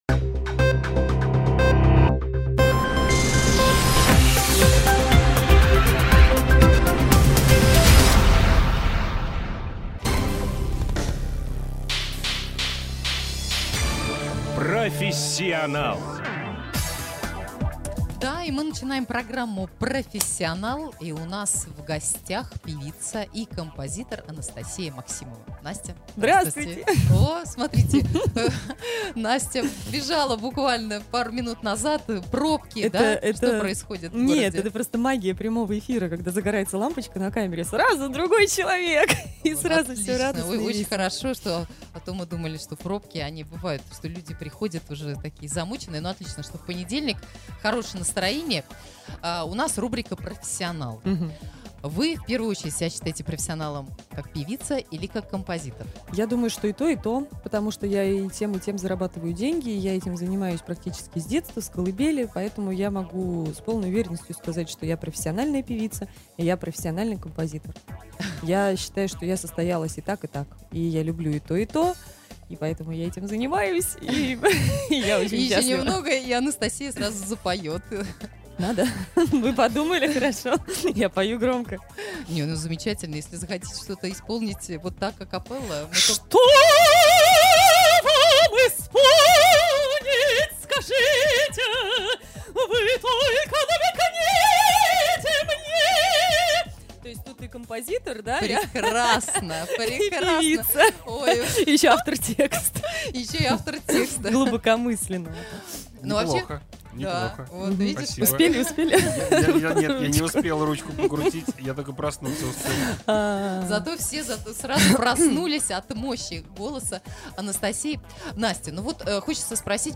Как со сцены "Музыкального ринга" шагнуть на оперную сцену и почему опера – это не скучно, узнали у гостьи студии [Фонтанки.Офис].